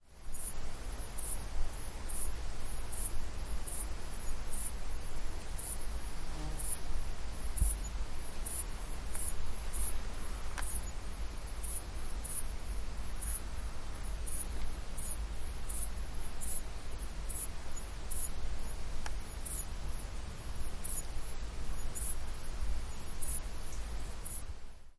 蟋蟀对猫的爬行
描述：孤独的板球寻找爱情
标签： 蟋蟀 昆虫 啁啾 性质 现场录音 板球
声道立体声